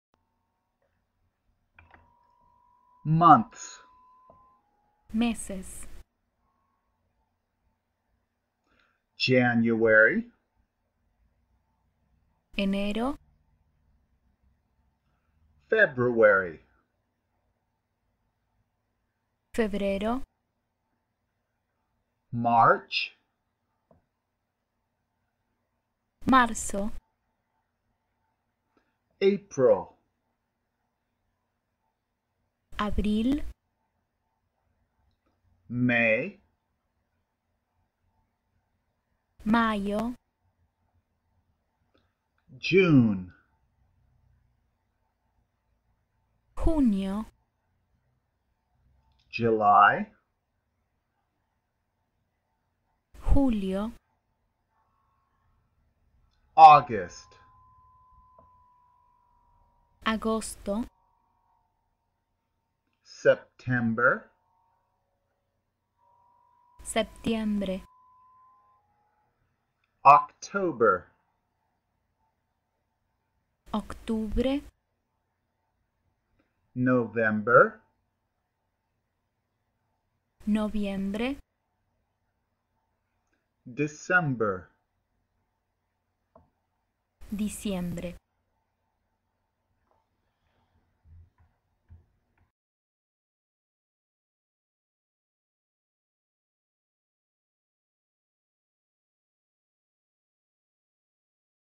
Hola a todos, En este sexto episodio vamos a escuchar Los meses del año en Español, repítelos y practícalos cuantas veces sea necesario.
Hello everyone, In this sixth episode we are going to listen to the Months of the year in Spanish, repeat them and practice them as many times as necessary.